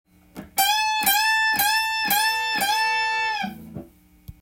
使いやすいようにAmペンタトニックスケールで譜面にしてみました。
そして、エレキギター特有のチョーキングを多用しているのも